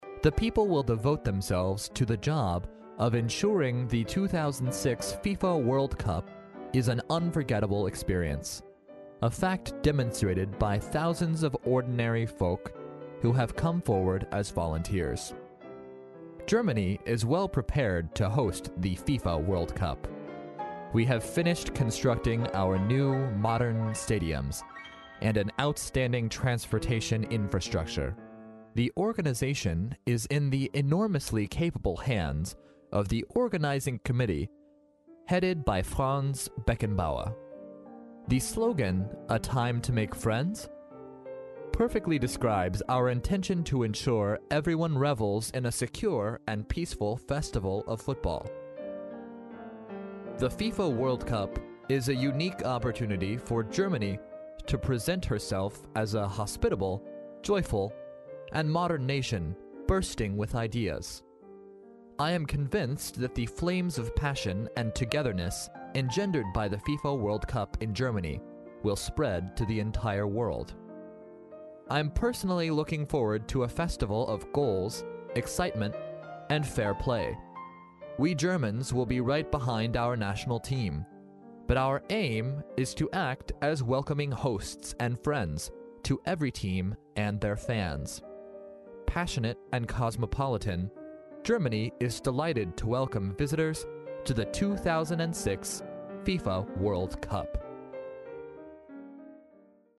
历史英雄名人演讲 第57期:2006年德国总理克尔的问候(2) 听力文件下载—在线英语听力室